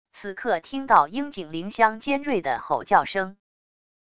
此刻听到樱井玲香尖锐的吼叫声wav音频生成系统WAV Audio Player